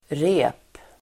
Uttal: [re:p]